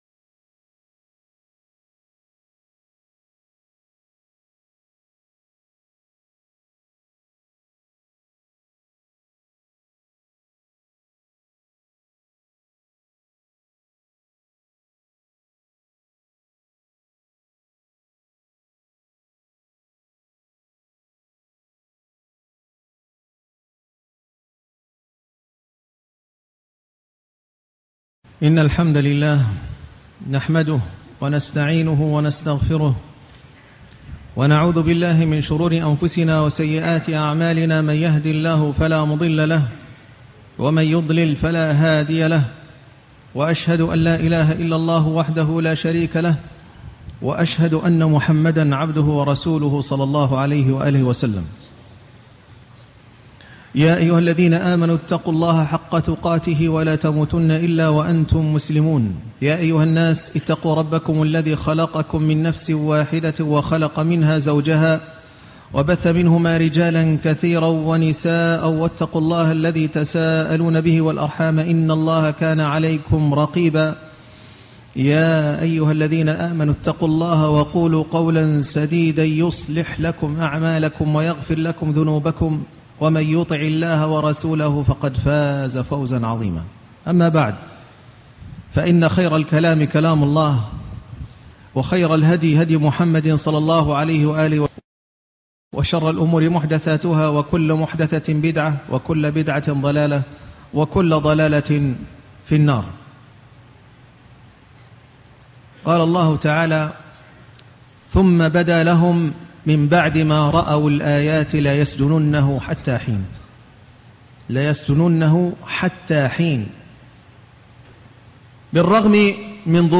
يوسف عليه السلام (5) - خطبة الجمعة